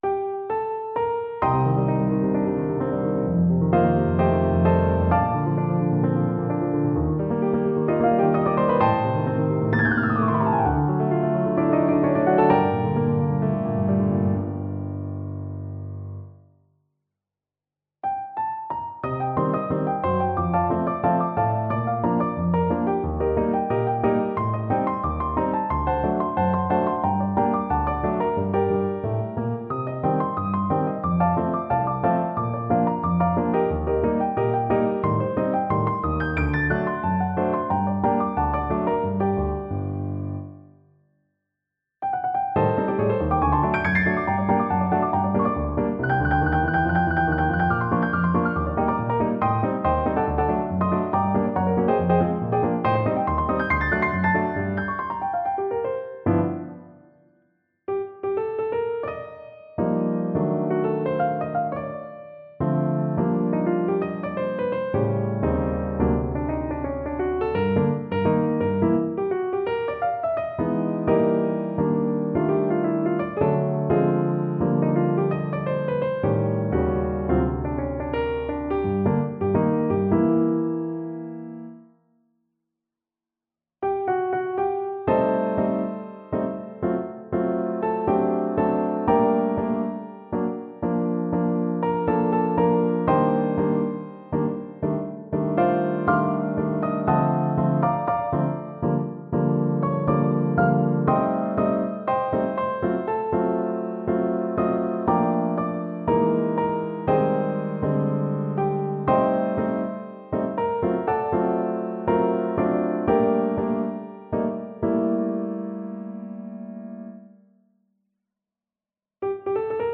piano 3